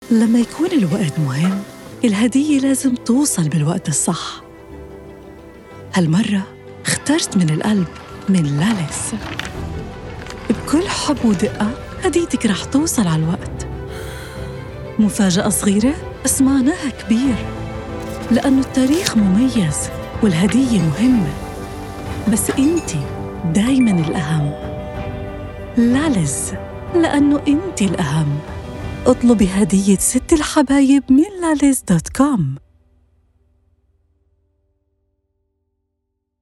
Palestinian – Vocal Global
Male Voices